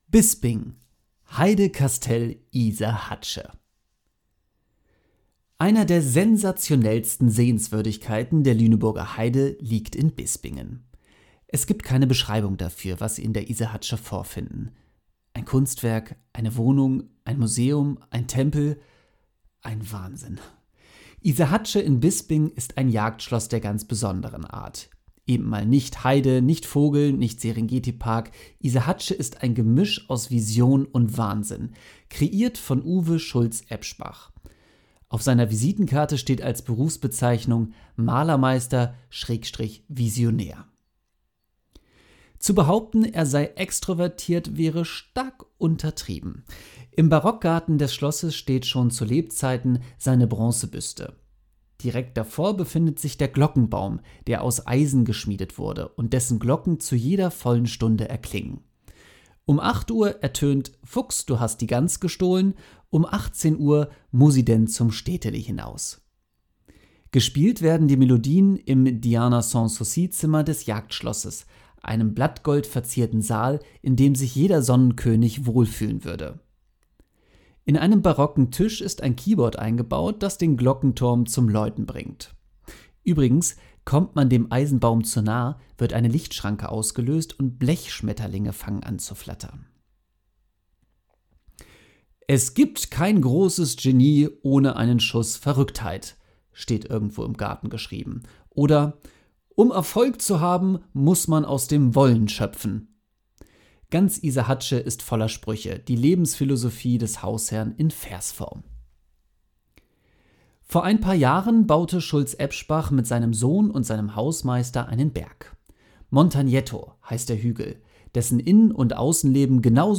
Iserhatsche Bispingen Text vorlesen lassen